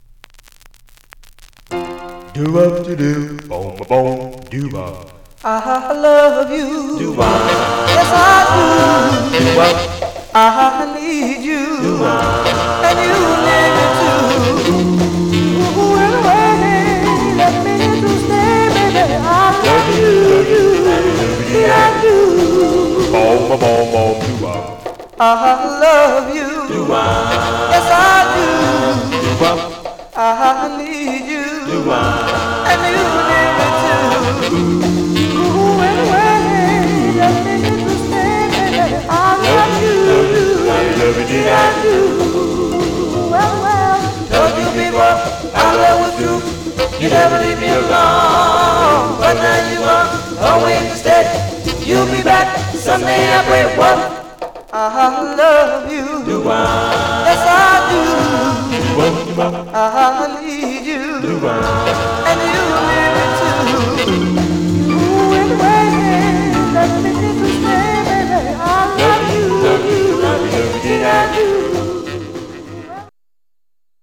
Some surface noise/wear
Mono
Male Black Group